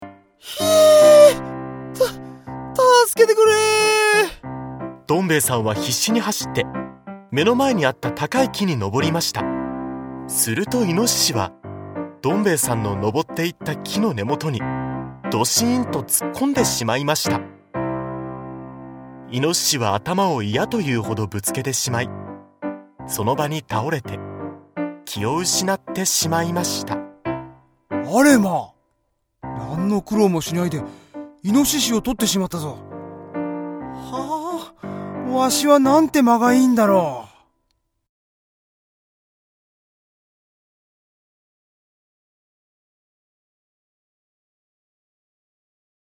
大人も子どもも楽しめる、童話オーディオブック！
大人も子どもも一緒になって、多彩なキャストと、楽しい音楽でお楽しみ下さい。
大人も子供も楽しめる童話オーディオブックを、多彩なキャストとBGMでお届けします。